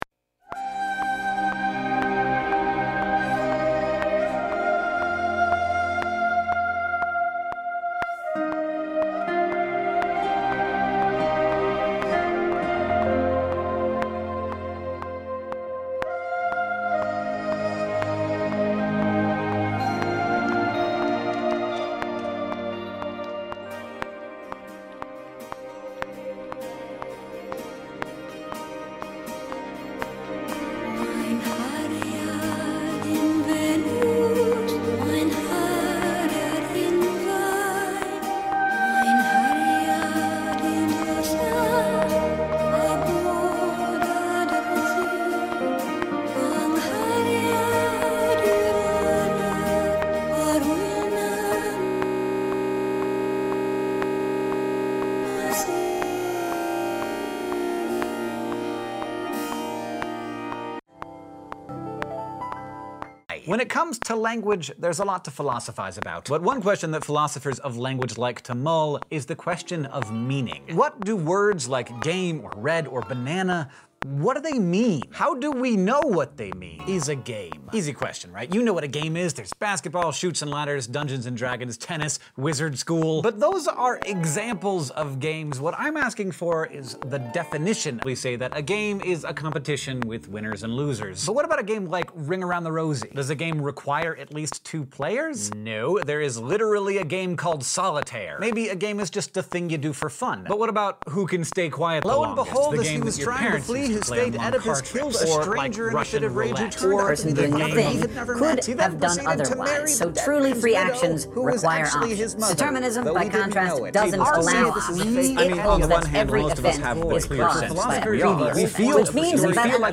This audio project replicates the experience of sitting in a graduate course that discusses theory. I want to use audio to mimic the mix of emotions and the confusion that occurs in my head as I try to make sense of my readings for graduate classes.